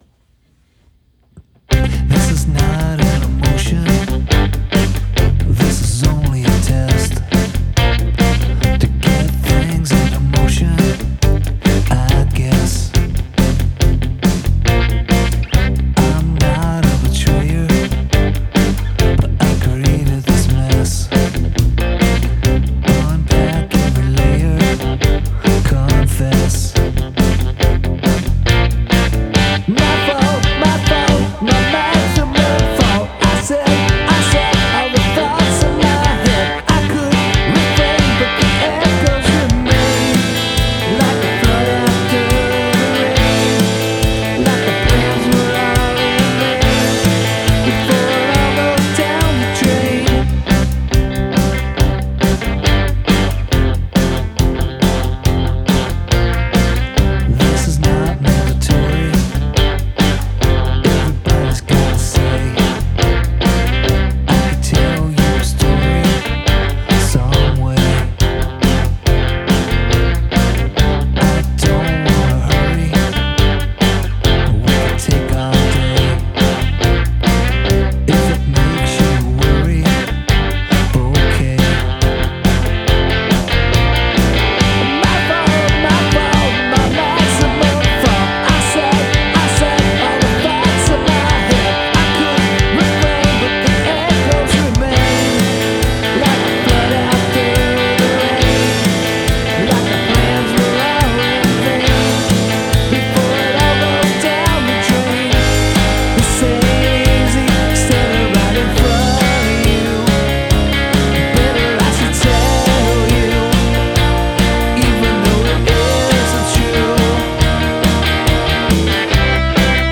This truly is great power-pop.